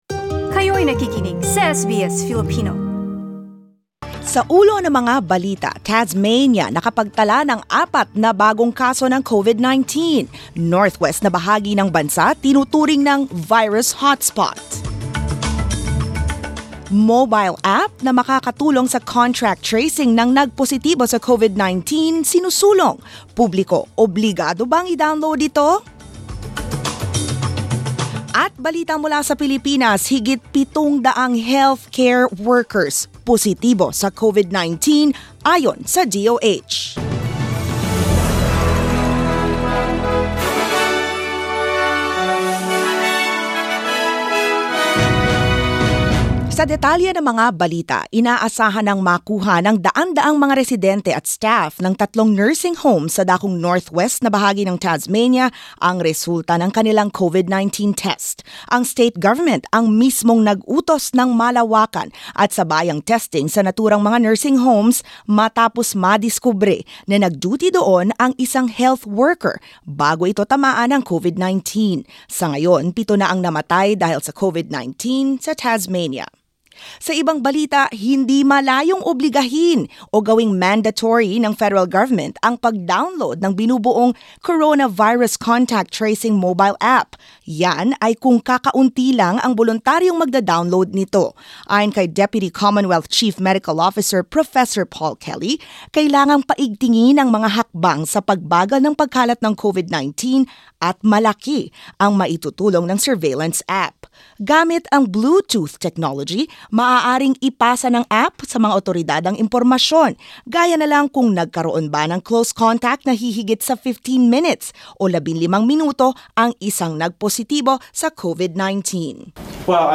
SBS News in Filipino, Saturday 18 April